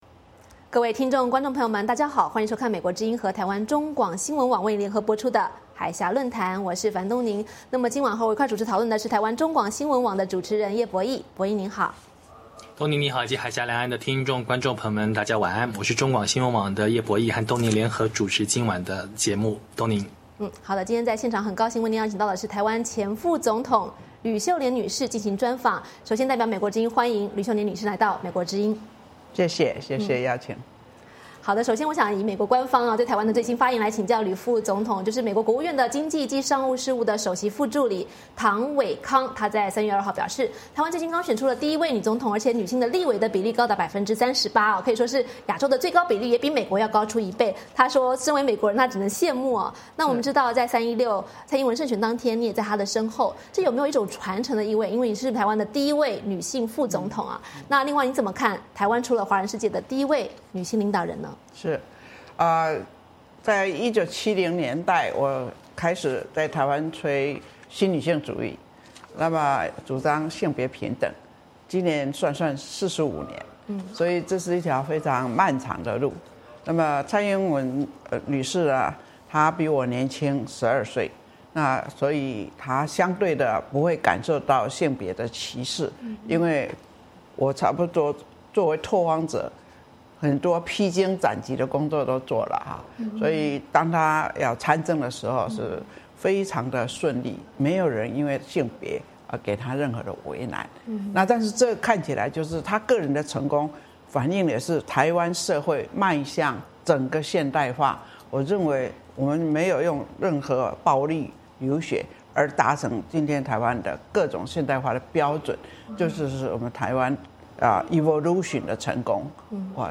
VOA专访台湾前副总统吕秀莲谈台湾新政局与中国女权